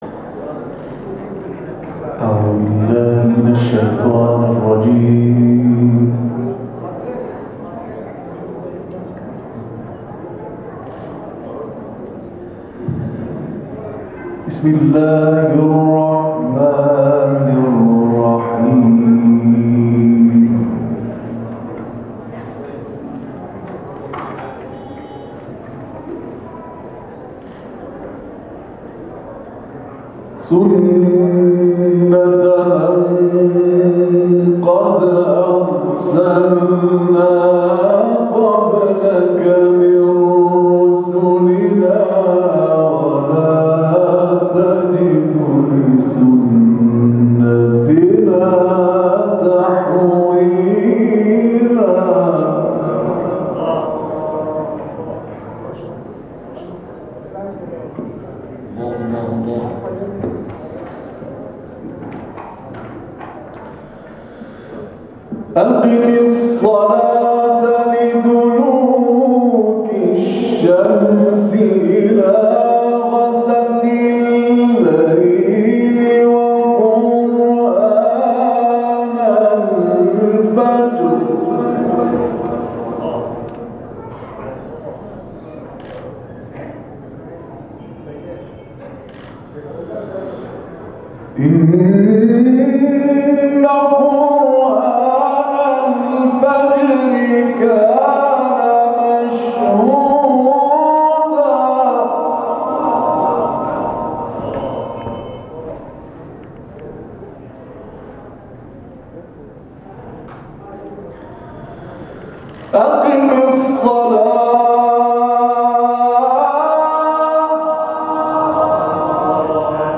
در ادامه صوت تلاوت‌ها تقدیم می‌شود.
تلاوت